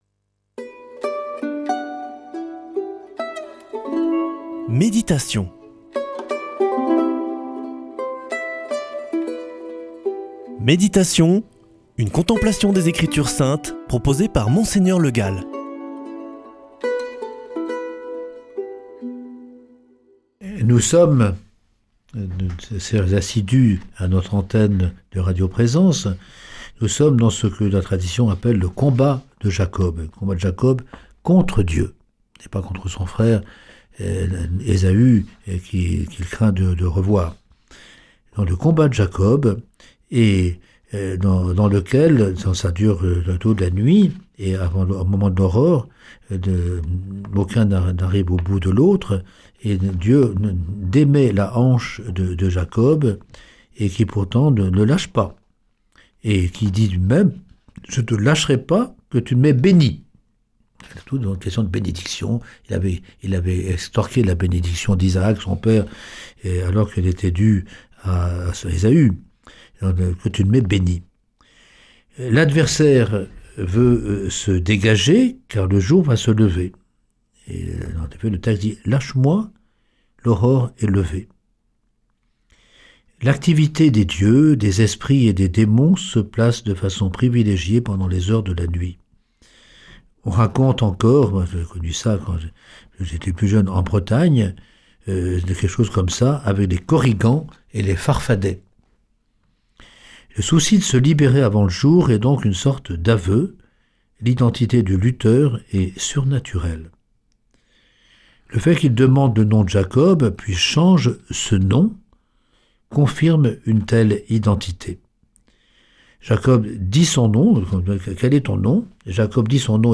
Méditation avec Mgr Le Gall
Présentateur